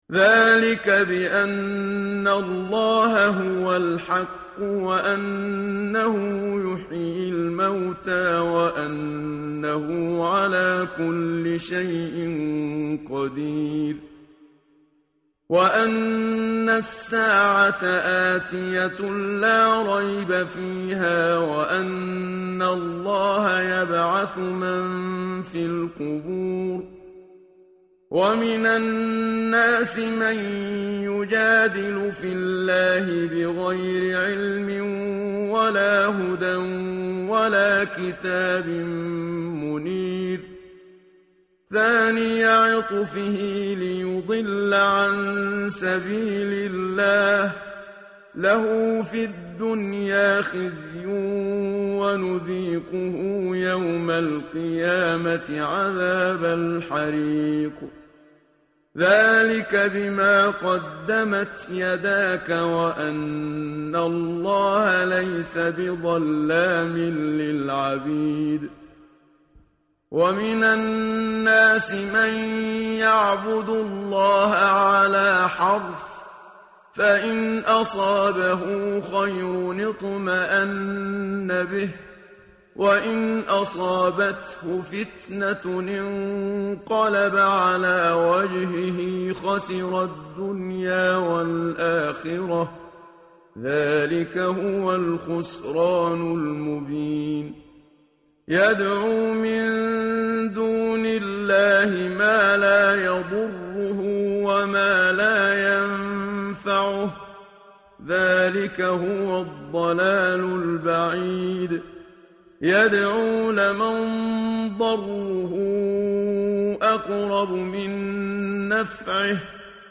tartil_menshavi_page_333.mp3